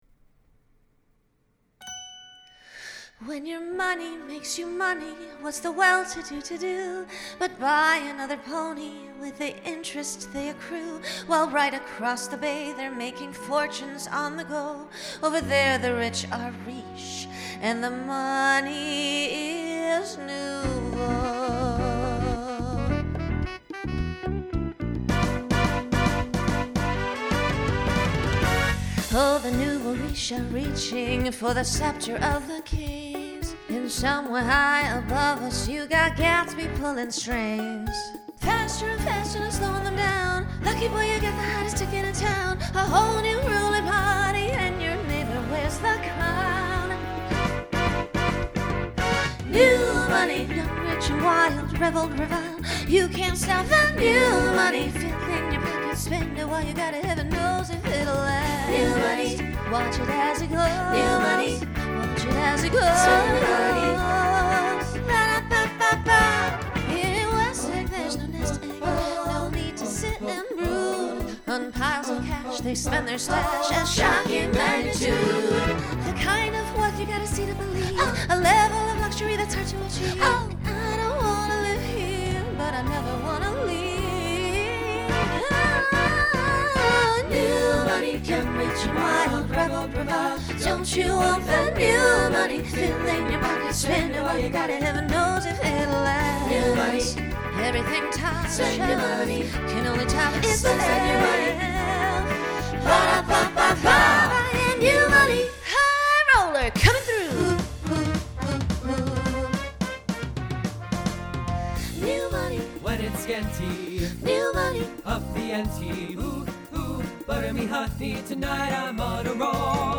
Genre Broadway/Film
Solo Feature Voicing SATB